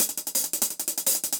Index of /musicradar/ultimate-hihat-samples/170bpm
UHH_AcoustiHatB_170-05.wav